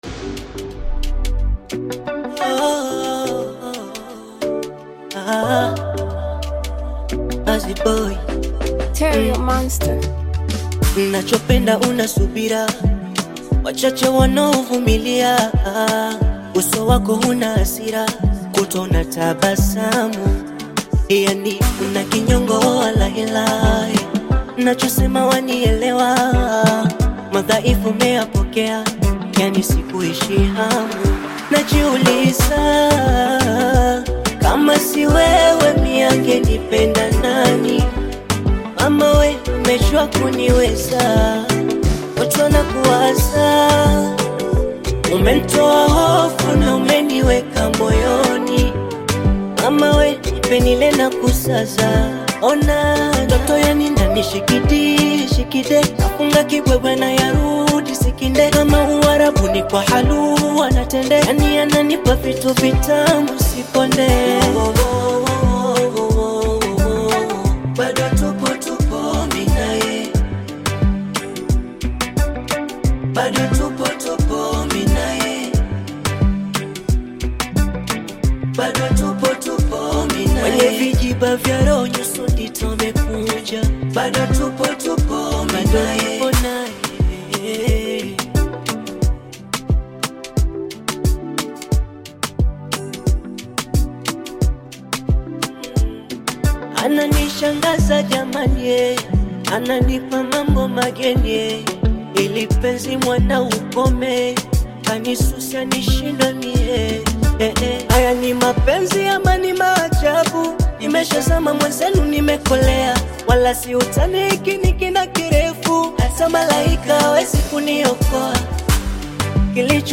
Tanzanian bongo flava artist, singer and songwriter
bongo flava love song